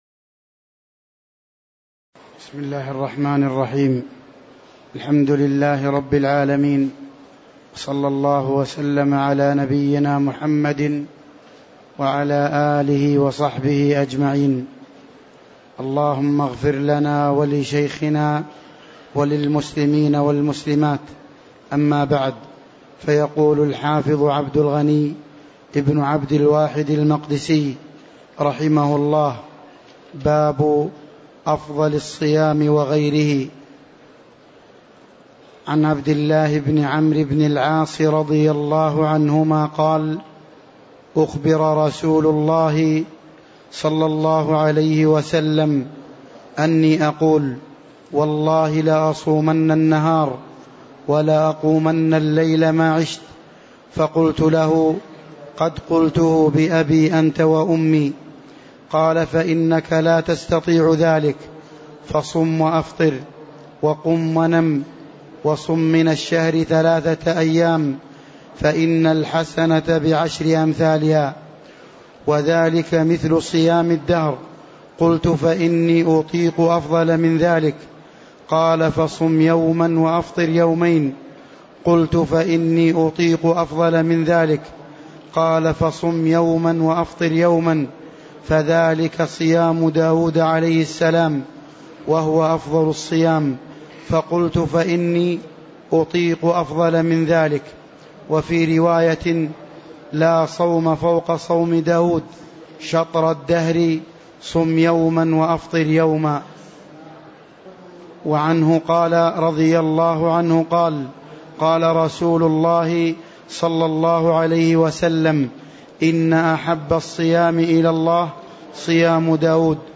تاريخ النشر ٤ جمادى الآخرة ١٤٤٤ هـ المكان: المسجد النبوي الشيخ